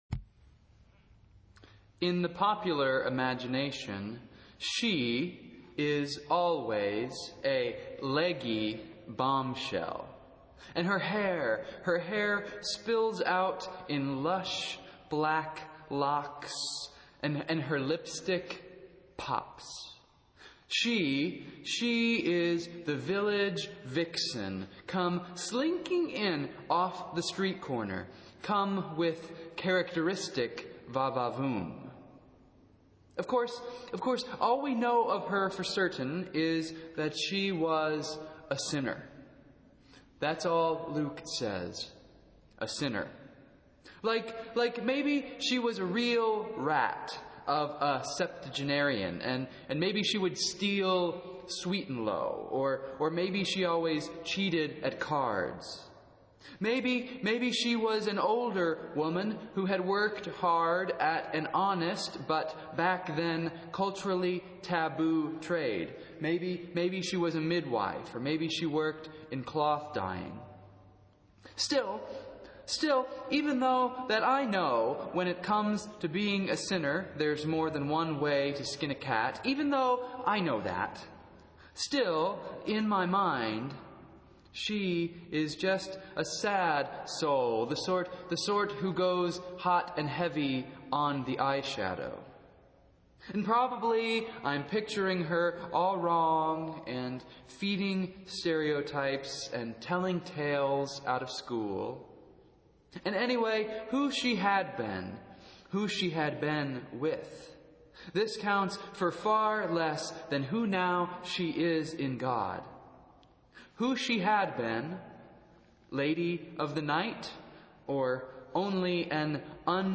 Festival Worship - Fourth Sunday after Pentecost